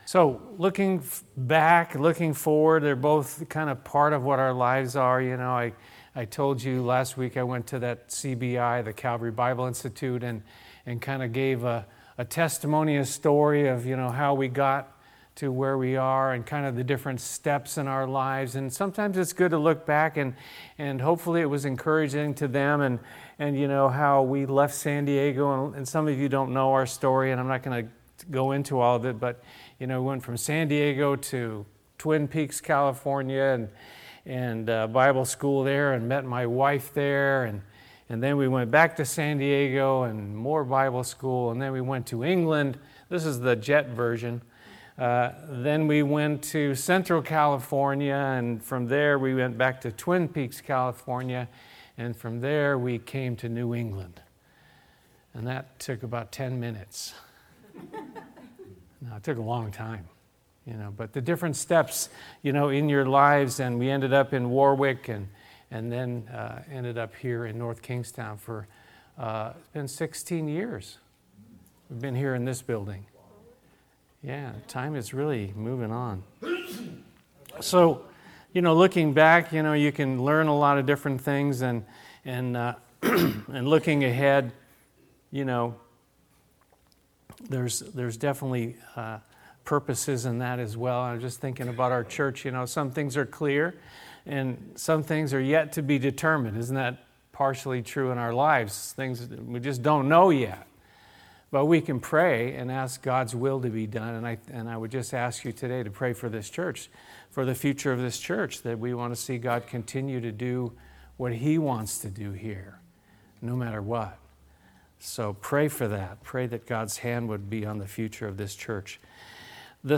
Looking Back, Looking Forward - Christian Sermons from Calvary Chapel Greenmeadow, North Kingstown, RI - Apple Podcasts